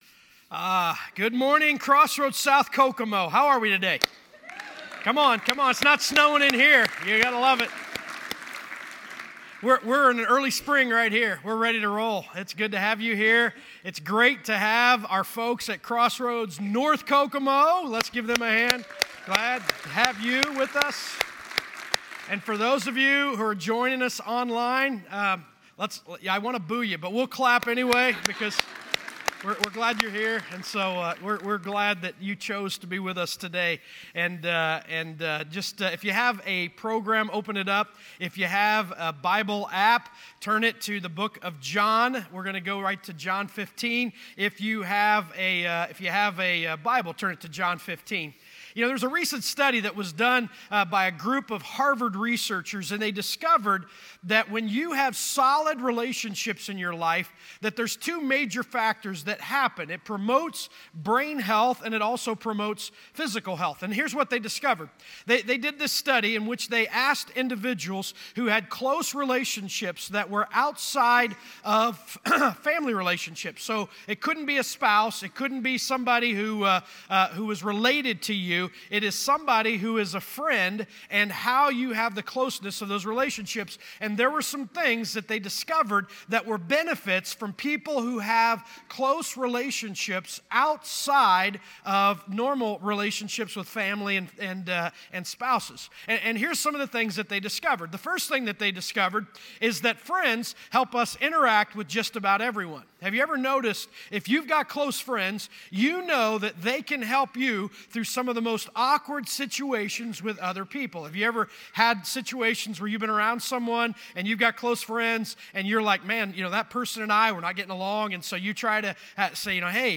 Crossroads Community Church - Audio Sermons